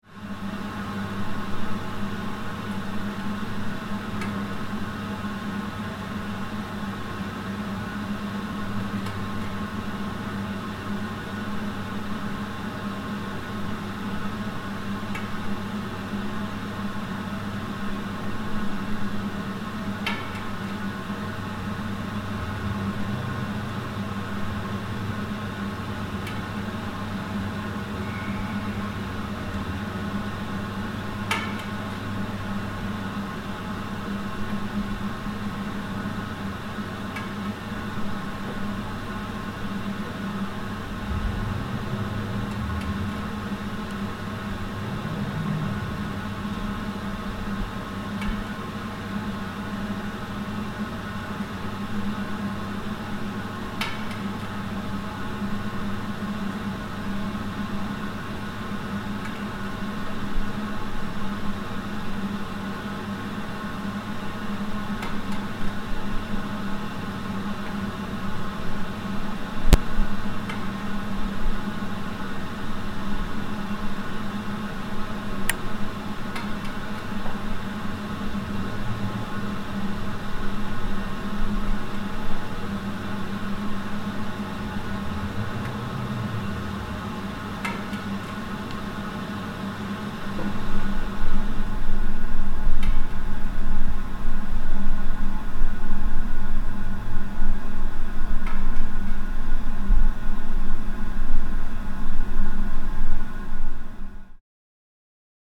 zahrádka ve dvoře
Takové zvuky dvora jsou docela záhadné. Po dešti, nebo po sněžení, je šepot kapiček slyšet všude.
zahrádka ve dvoře Tagy: voda okna a dveře Autor